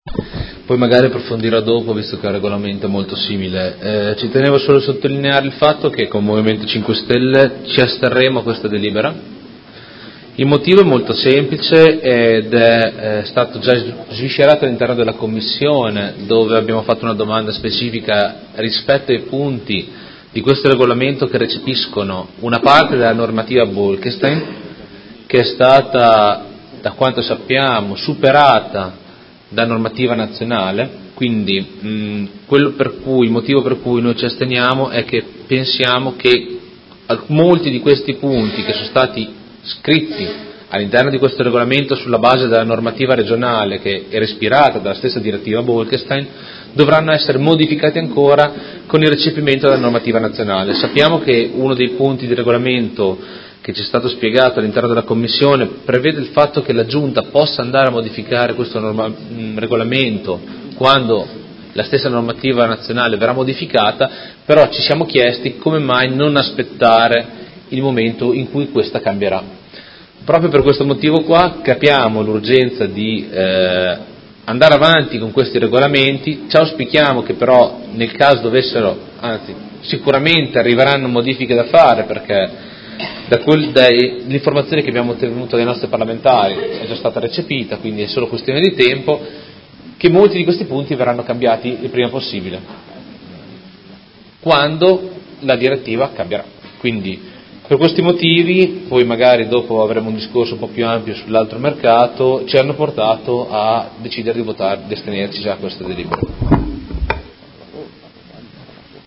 Luca Fantoni — Sito Audio Consiglio Comunale